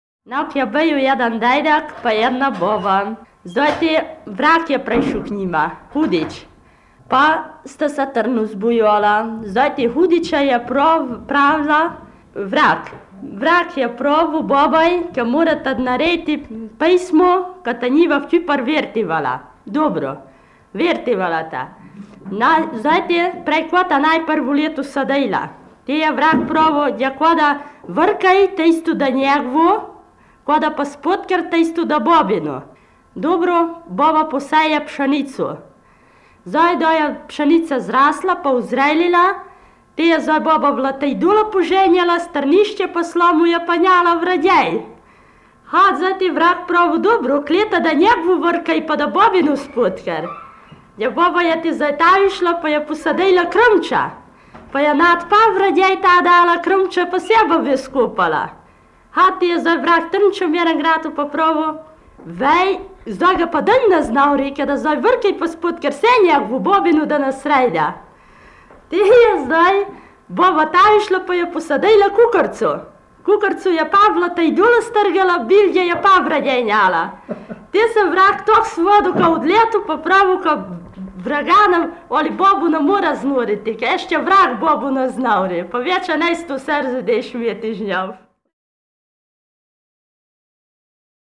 V knjigi so zbrane porabske pravljice in povedke, ki jih je leta 1970 posnel Milko Matičetov na magnetofonske trakove.
Dodana je zgoščenka s tonskimi posnetki trinajstih pravljic in povedk v obeh različicah porabskega narečja (števanovskem in gornjeseniškem).